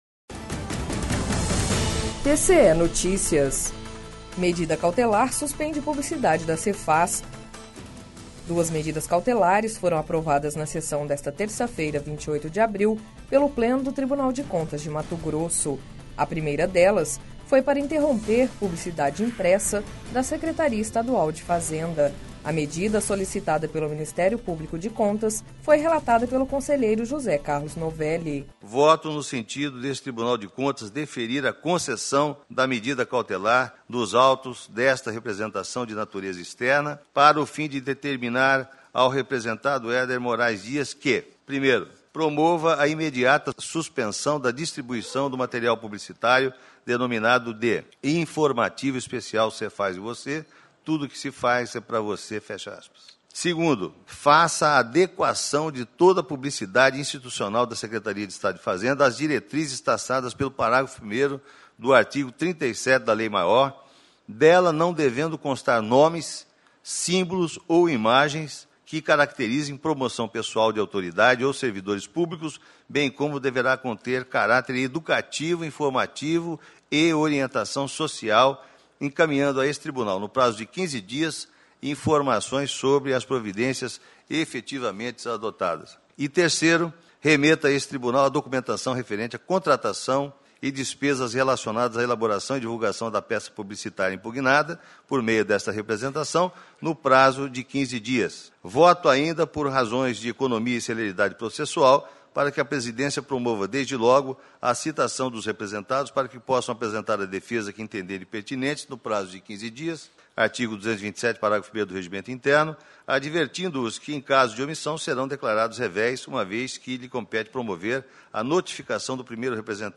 Sonora: José Carlos Novelli – conselheiro do TCE-MT